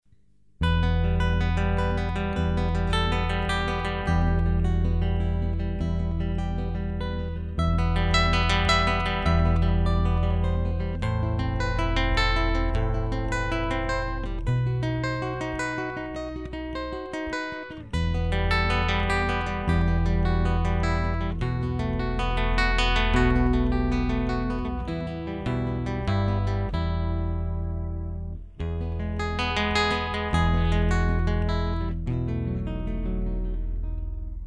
Gitarrist